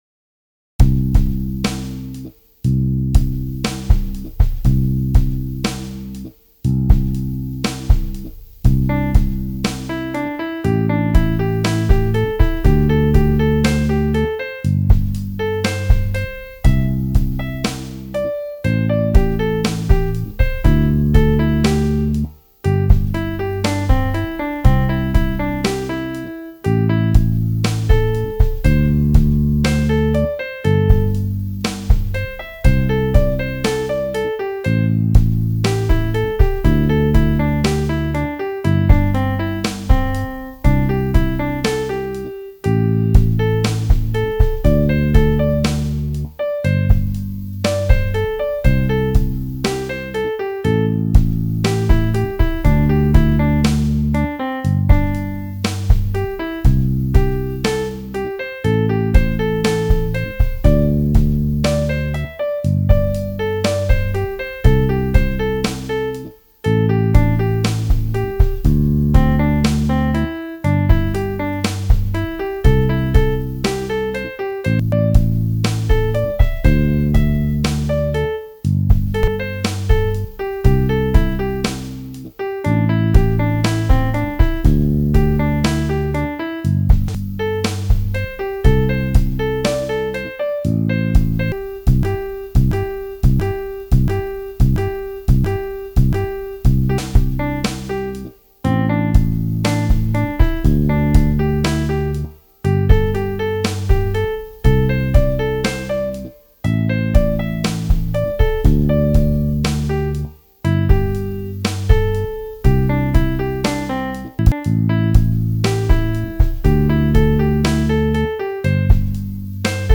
C Major Pentatonic Medium Tempo